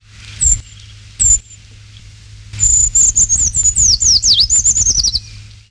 Grimpereau des bois
Certhia familiaris
grimpereau_b.mp3